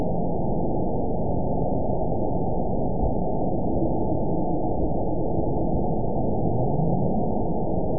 event 920592 date 03/31/24 time 17:52:58 GMT (1 year, 8 months ago) score 9.40 location TSS-AB02 detected by nrw target species NRW annotations +NRW Spectrogram: Frequency (kHz) vs. Time (s) audio not available .wav